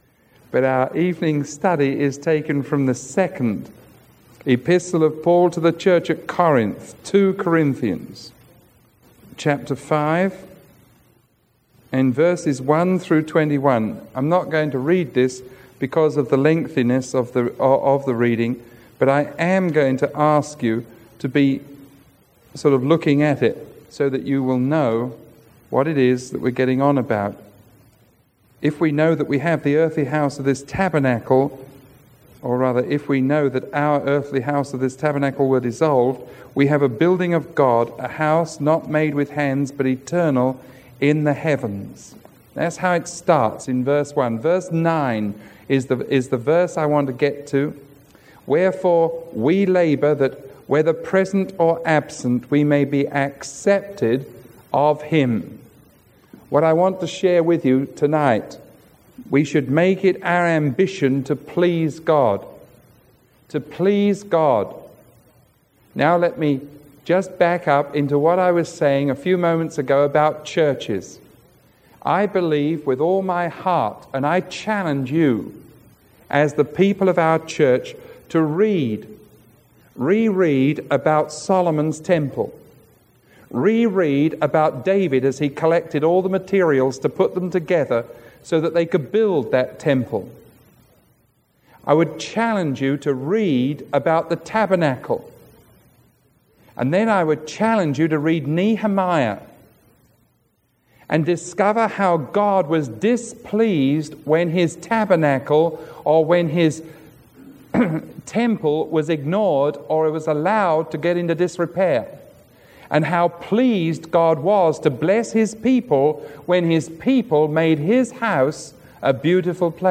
Sermon 0314AB recorded on March 11